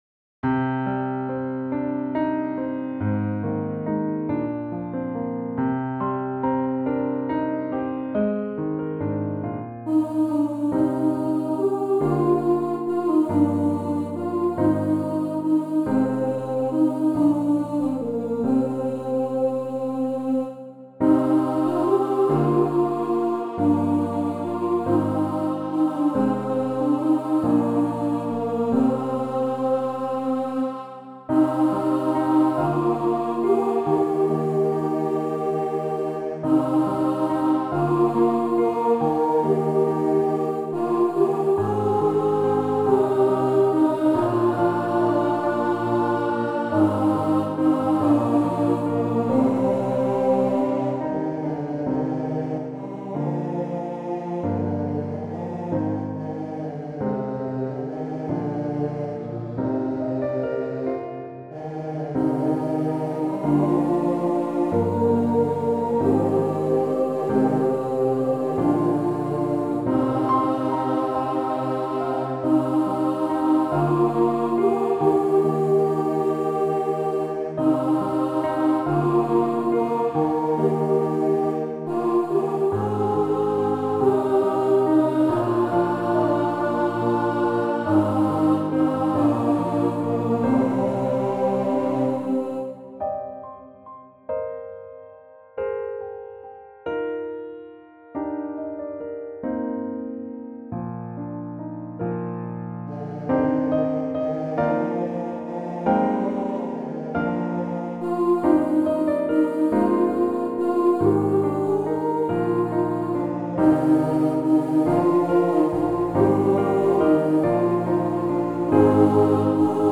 Arranged in the key of C minor. Accompanied by piano.
Soprano and Alto are unison where no splits occur.